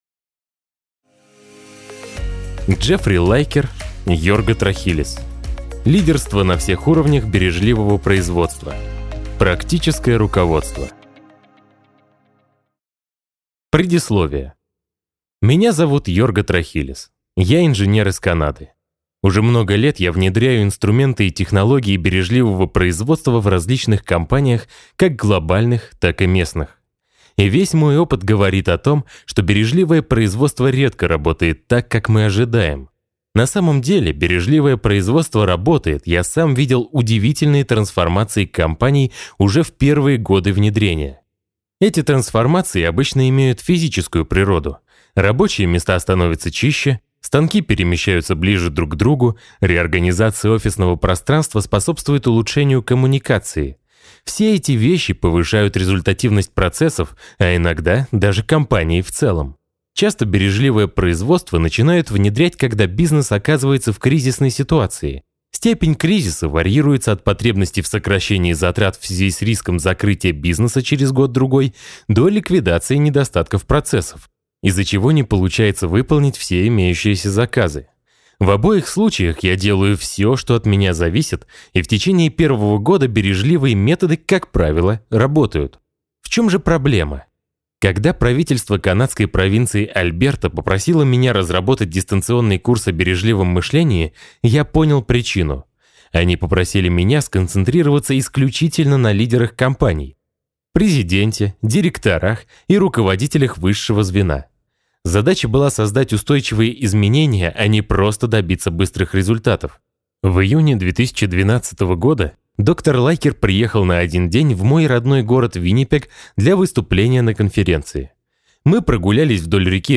Аудиокнига Лидерство на всех уровнях бережливого производства. Практическое руководство | Библиотека аудиокниг